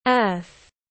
Trái đất tiếng anh gọi là earth, phiên âm tiếng anh đọc là /ɜːθ/.
Earth /ɜːθ/